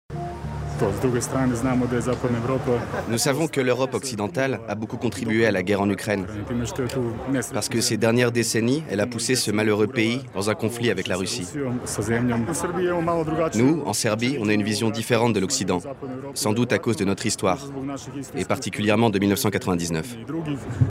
Voix Off Reportage C Politique
18 - 30 ans - Ténor